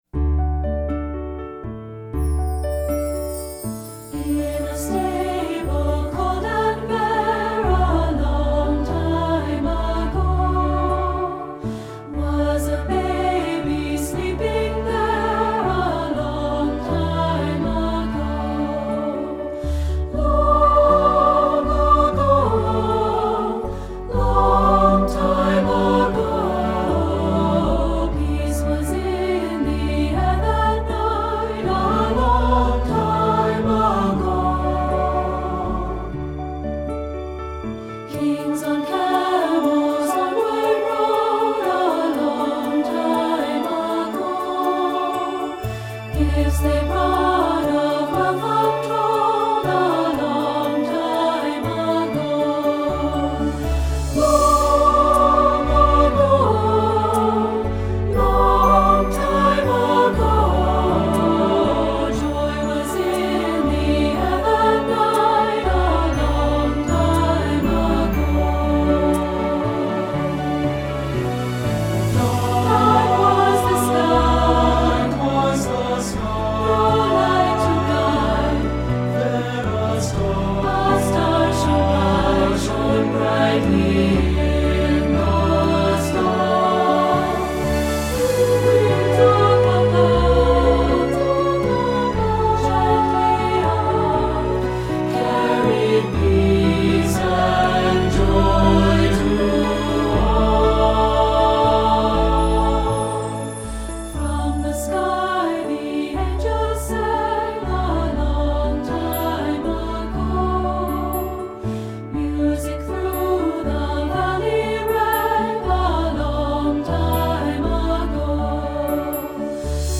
Voicing: 3-Part Mixed and Piano